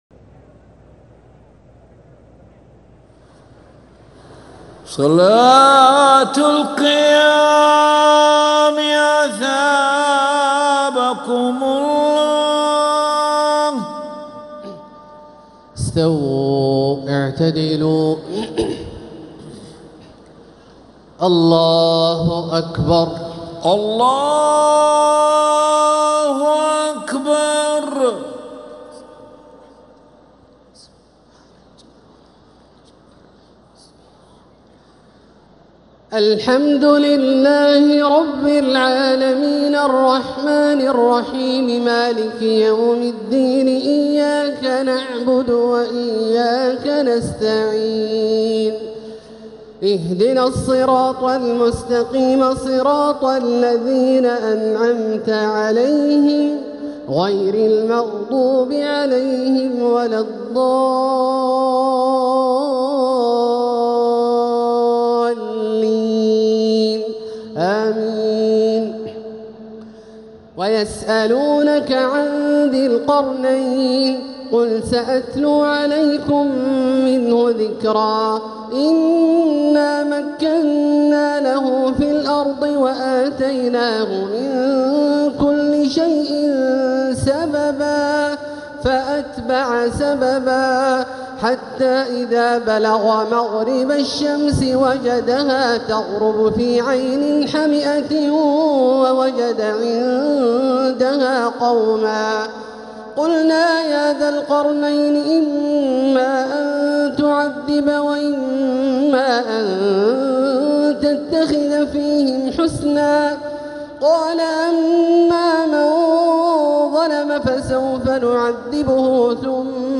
تراويح ليلة 21 رمضان 1446هـ من سورتي الكهف (83-110) و مريم (1-76) | Taraweeh 21st night Ramadan 1446H Surah Al-Kahf and Maryam > تراويح الحرم المكي عام 1446 🕋 > التراويح - تلاوات الحرمين